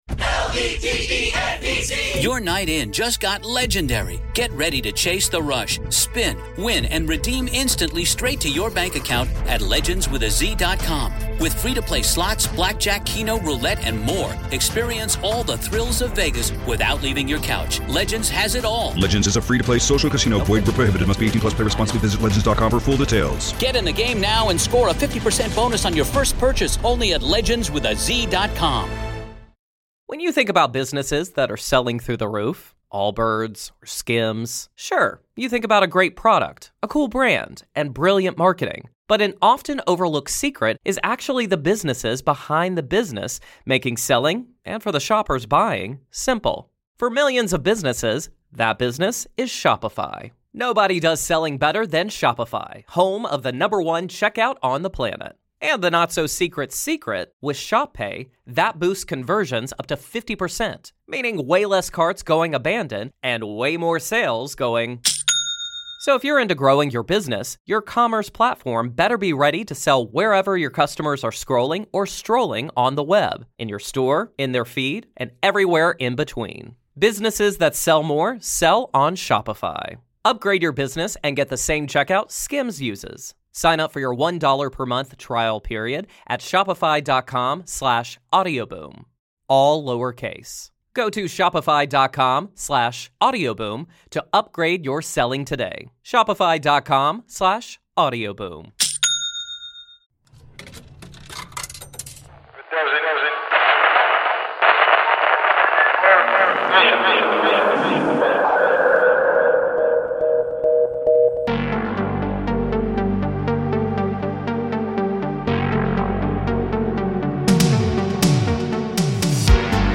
Season 14 Episode 9 of Monsters Among Us Podcast, true paranormal stories of ghosts, cryptids, UFOs and more told by the witnesses themselves.